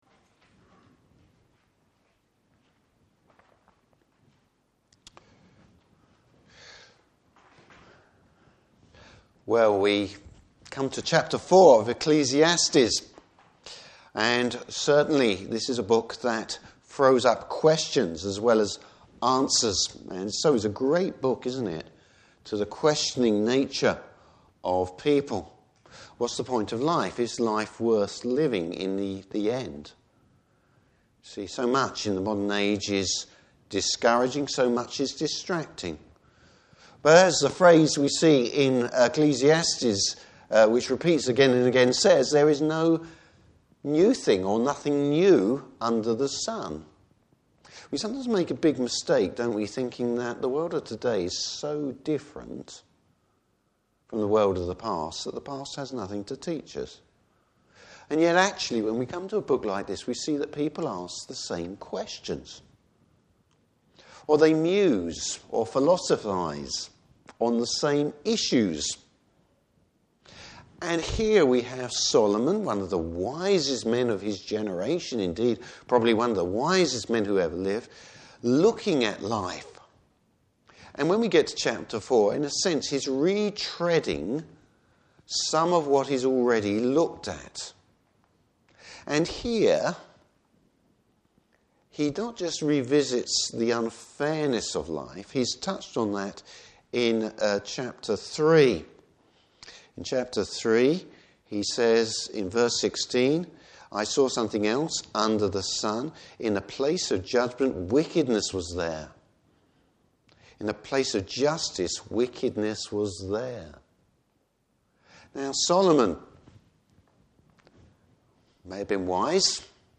Service Type: Morning Service Bible Text: Ecclesiastes 4.